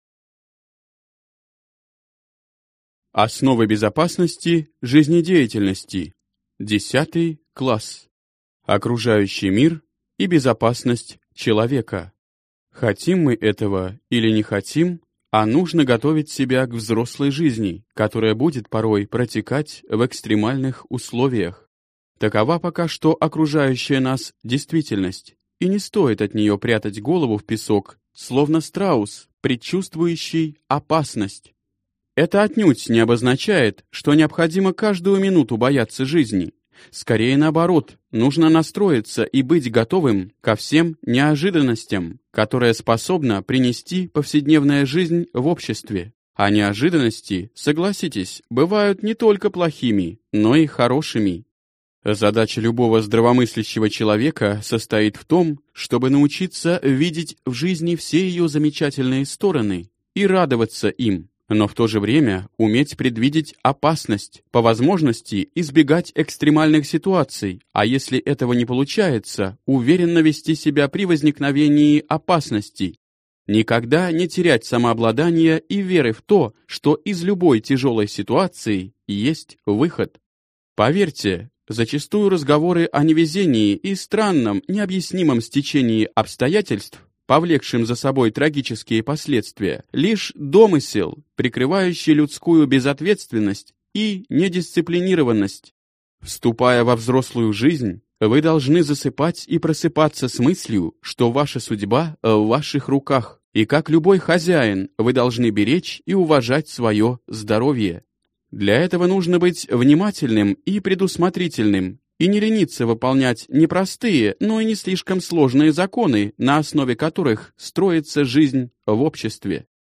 Аудиокнига 10 класс. Основы безопасности жизнедеятельности | Библиотека аудиокниг
Прослушать и бесплатно скачать фрагмент аудиокниги